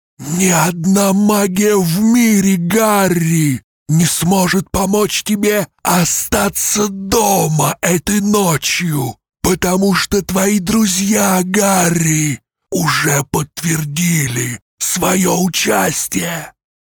Муж, Пародия(Воландеморт)
Звуковая карта: Auditnt id22 Микрофон: Neumann TLM 103 Преамп: Long voice master